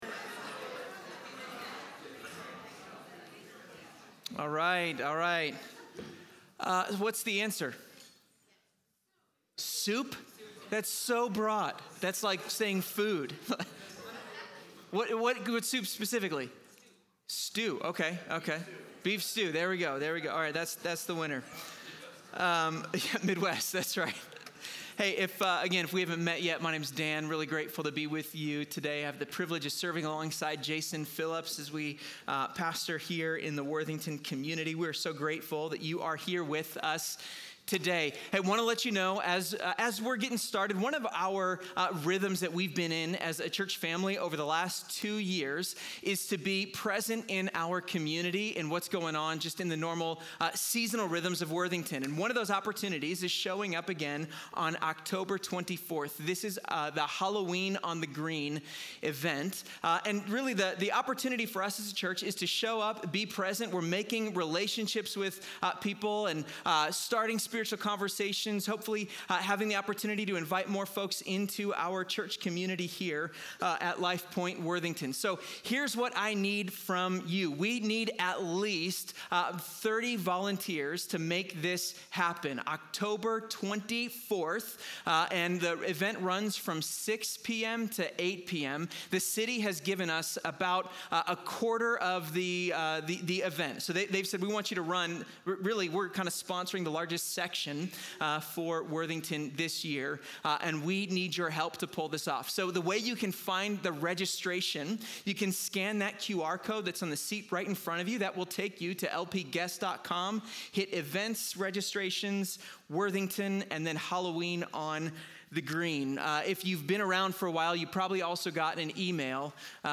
In this message on James 1:19-27, the speaker challenges us to put our loyalty to Jesus into practice—not just by hearing God’s Word, but by living it out. He explores how true faith cultivates self-control over anger, humility in receiving scripture, and obedience in daily life. Rather than empty religious rituals, allegiance to Christ is shown through concrete love for the vulnerable and a life kept unstained by the world.